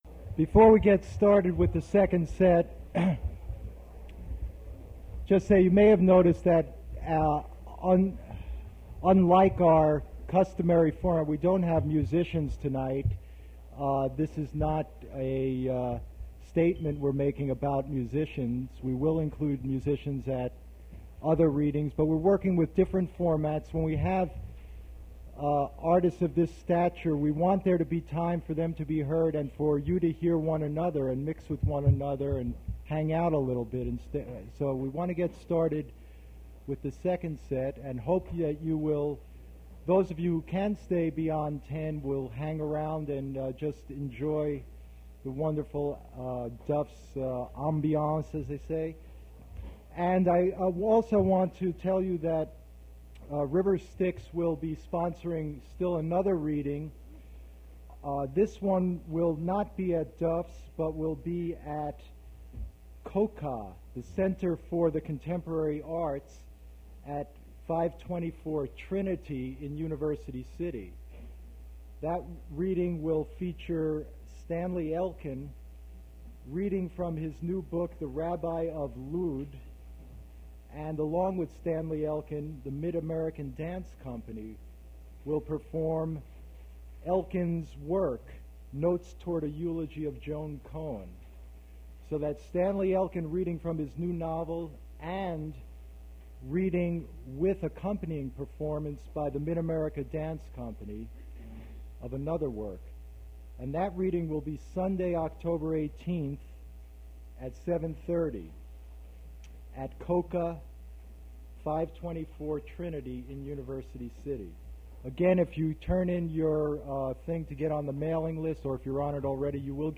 Poetry reading featuring Donald Finkel
Attributes Attribute Name Values Description Donald Finkel poetry reading at Duff's Restaurant.
mp3 edited access file was created from unedited access file which was sourced from preservation WAV file that was generated from original audio cassette.
Buzzing in background of recording